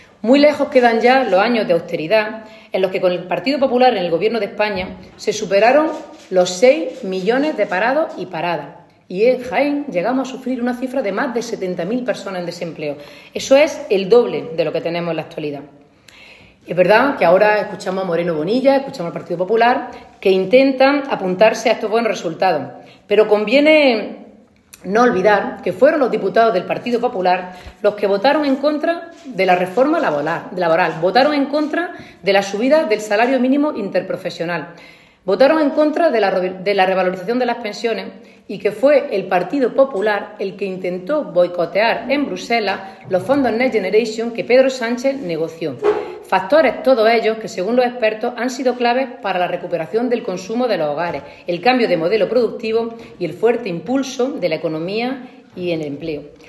En rueda de prensa, Cobo destacó que las políticas laborales del Gobierno y el despliegue de los fondos Next Generation han permitido impulsar “un cambio de modelo productivo para adaptar la economía a la transformación tecnológica” que estamos viviendo.
Ana-Cobo-empleo-2.mp3